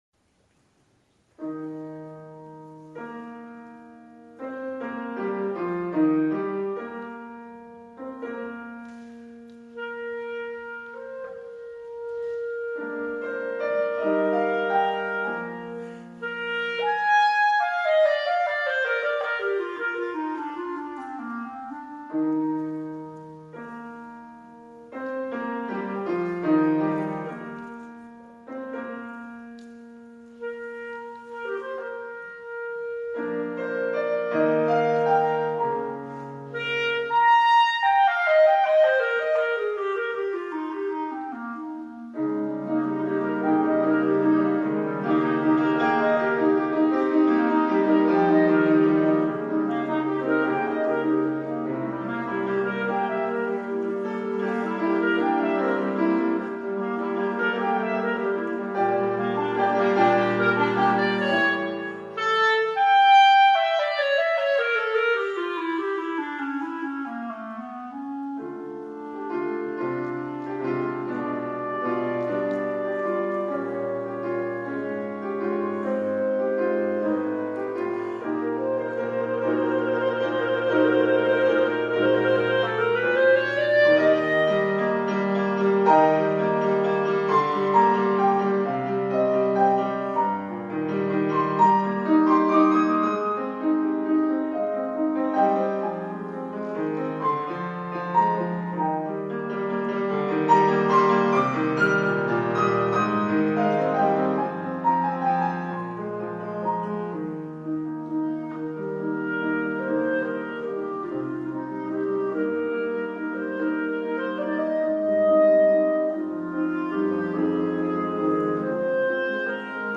è una pagina brillante e virtuosistica
il brano viene presentato per clarinetto e pianoforte